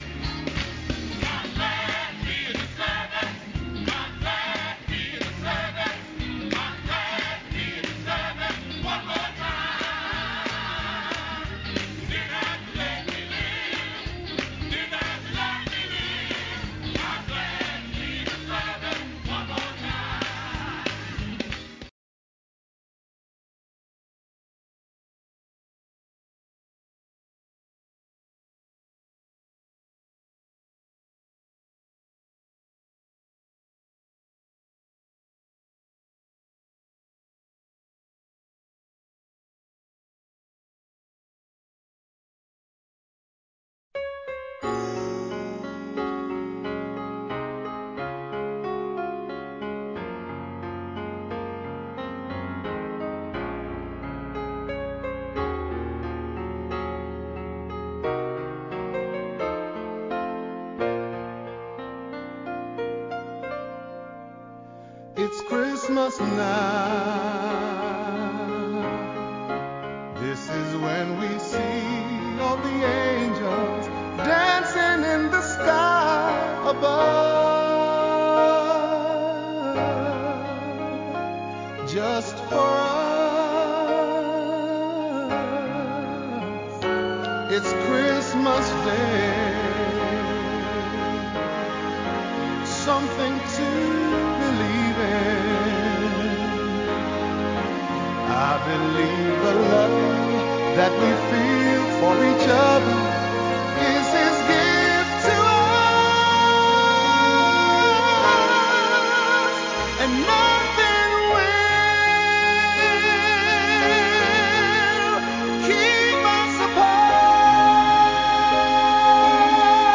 7:30 A.M. Service: Preparing For Christmas